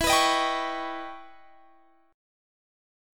EmM13 Chord
Listen to EmM13 strummed